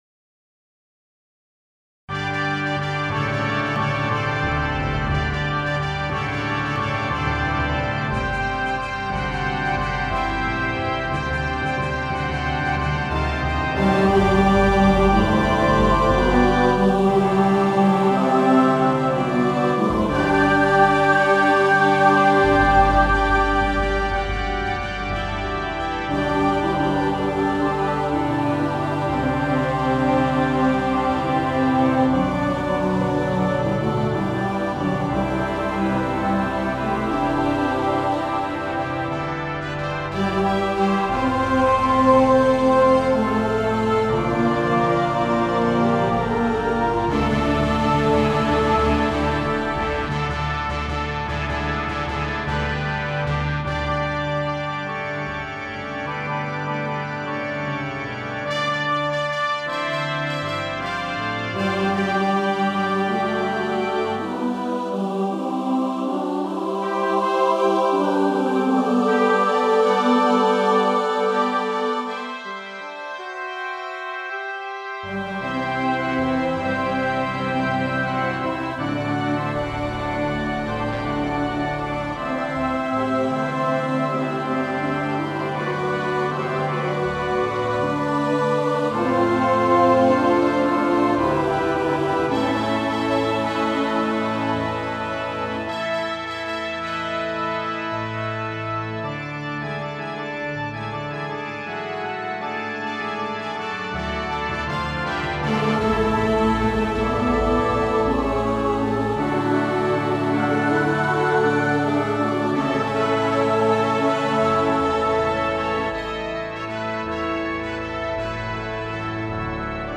• Music Type: Choral
• Voicing: SATB
• Accompaniment: Organ
This ebullient anthem